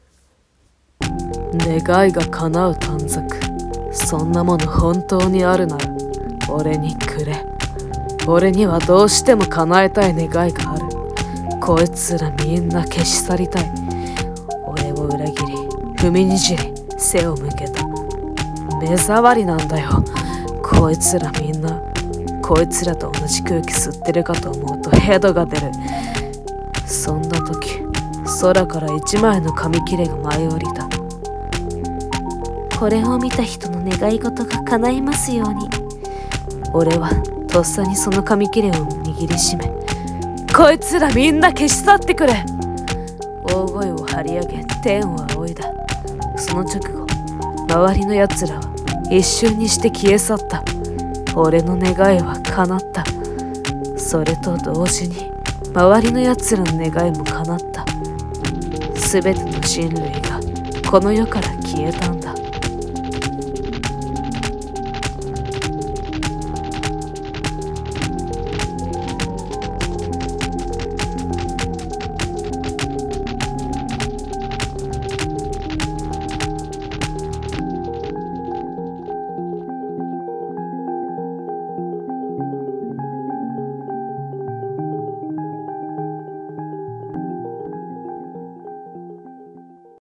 【 声劇台本 】全ての願いが叶う時